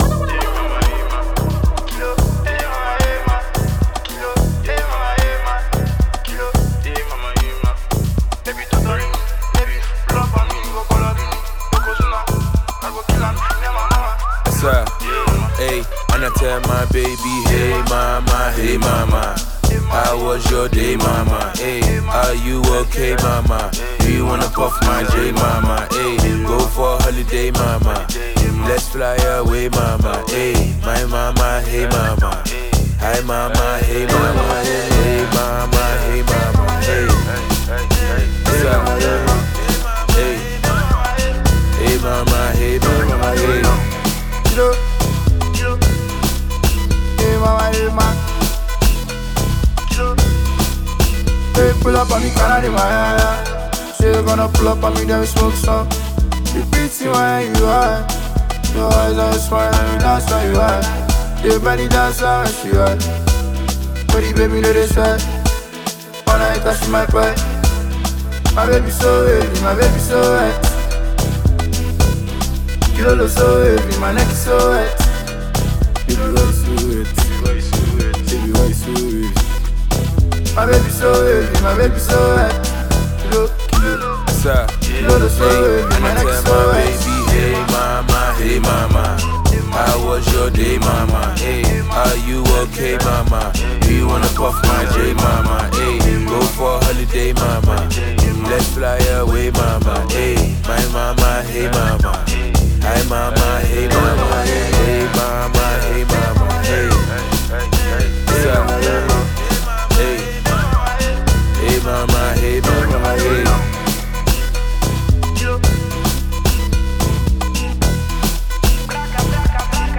” a track that radiates warmth and celebration.